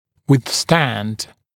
[wɪð’stænd][уиз’стэнд]противостоять, сопротивляться; выдерживать